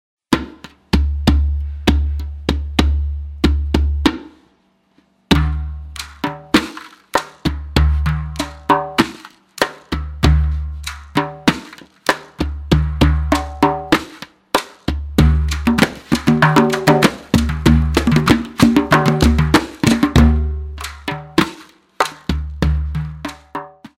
Percussion and Piano Music